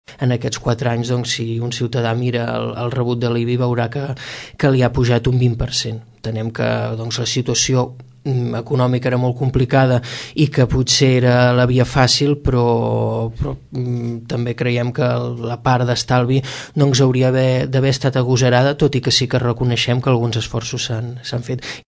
En una entrevista al programa L’entrevista amb els polítics de Ràdio Tordera, Xavier Martin qualifica de ”discret” el balanç de la legislatura perquè, segons el regidor, ”l’Ajuntament ha tingut encerts i errors en la gestió municipal”.